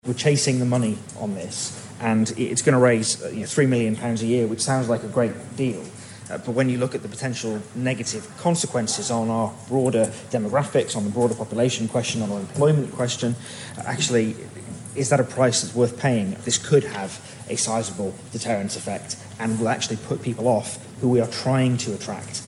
But in Tynwald today (16 July) Ramsey MHK Lawrie Hooper warned it could negatively affect inward migration: